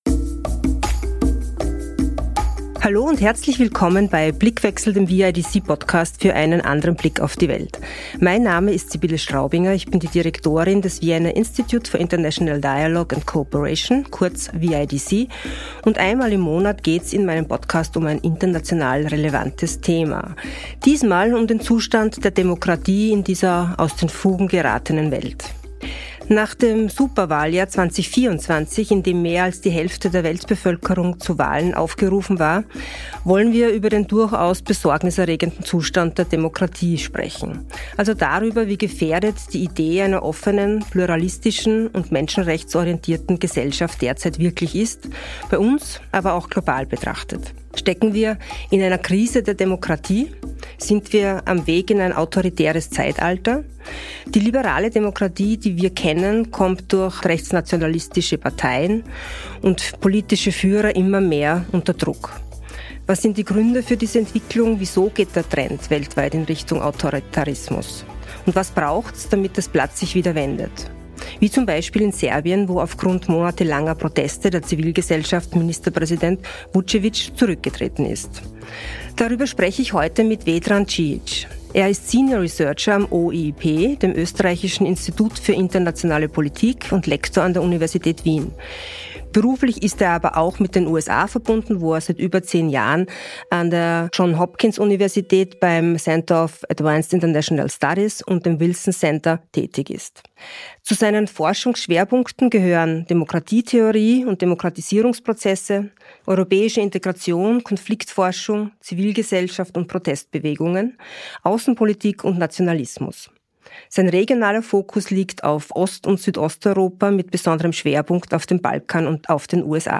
Das Superwahljahr 2024, in dem rund die Hälfte der Weltbevölkerung gewählt hat, war für diese Entwicklung ein Brandbeschleuniger. Sind wir auf dem Weg in ein autoritäres Zeitalter und was braucht es, damit sich das Blatt wieder wendet? Ein zukunftsweisendes Gespräch